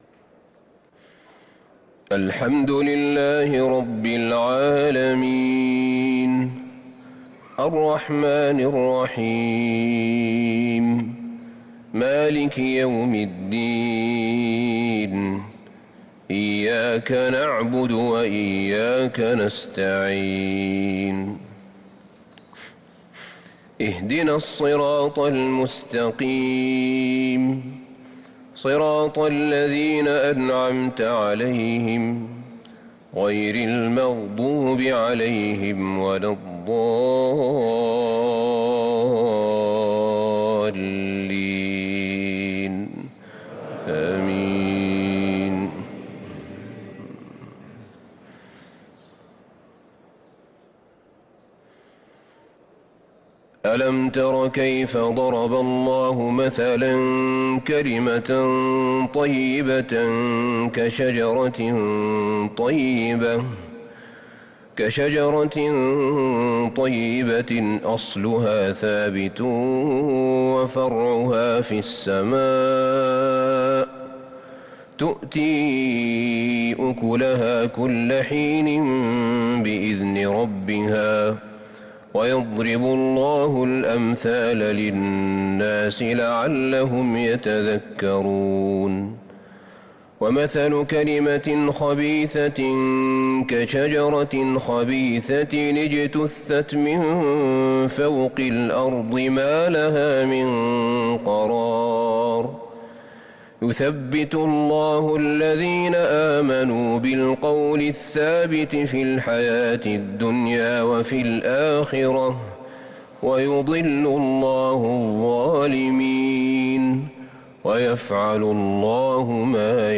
صلاة الفجر للقارئ أحمد بن طالب حميد 19 محرم 1444 هـ